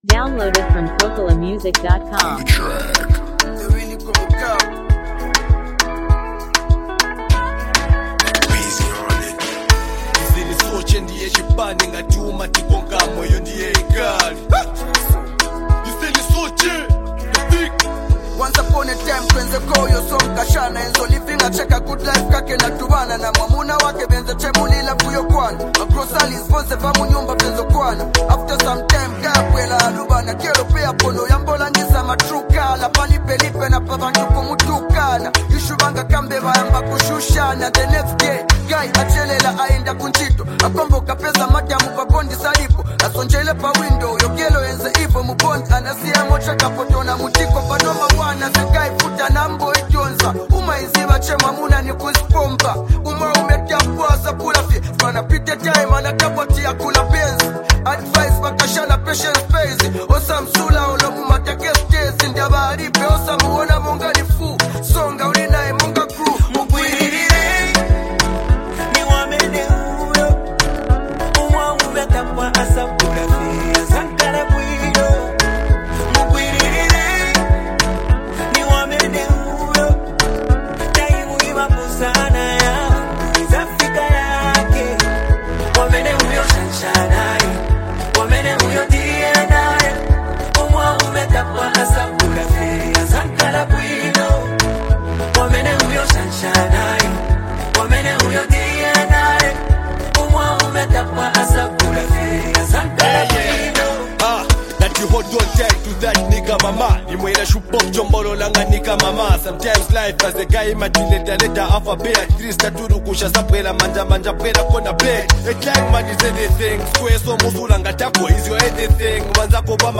Over a hard-hitting beat
a gritty and fearless verse
comes across as a motivational chant